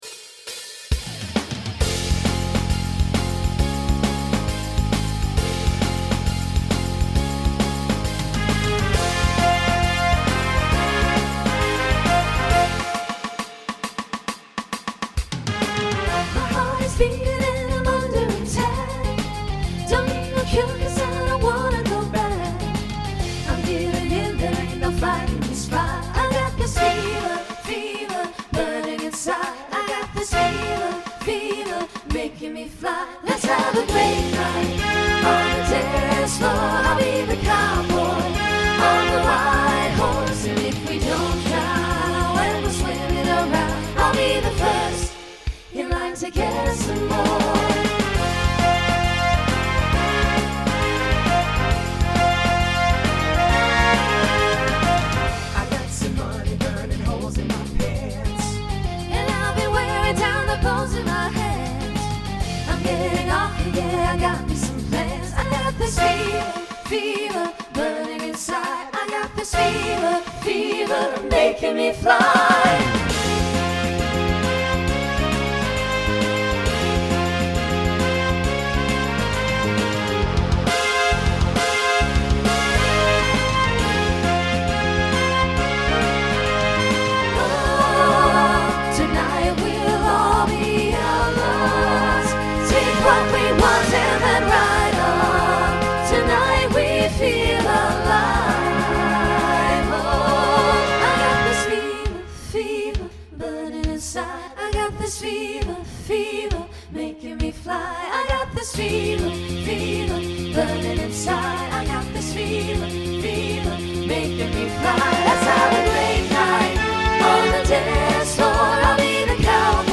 Voicing SATB Instrumental combo Genre Rock